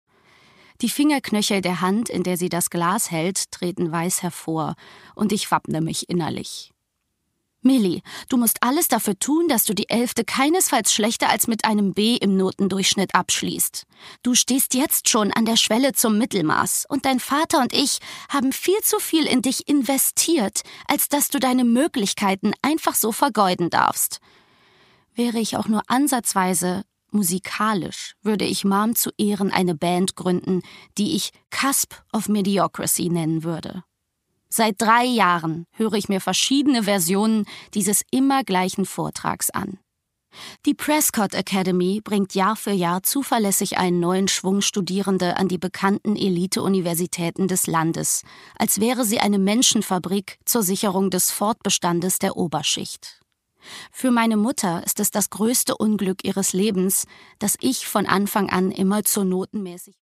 Produkttyp: Hörbuch-Download
Fassung: Ungekürzte Lesung